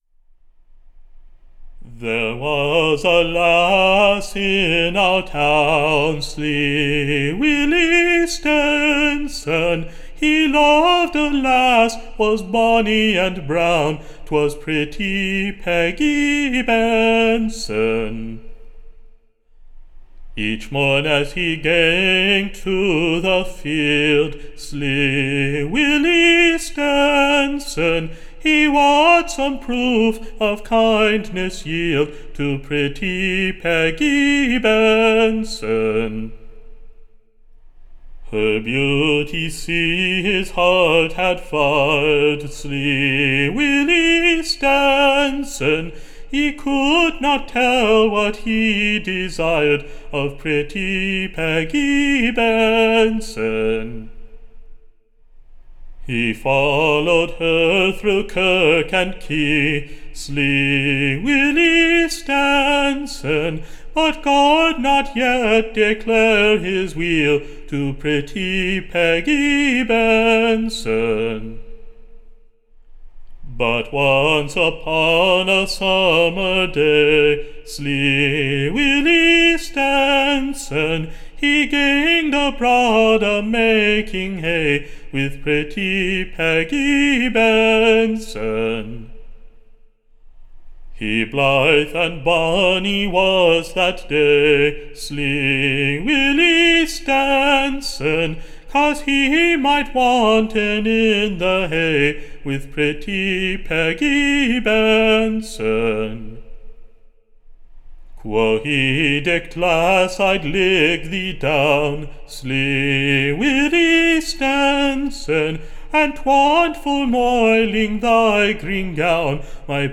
Recording Information Ballad Title Oppertunity Lost, Or / The Scotch Lover Defeated.
To a pleasant Northern tune.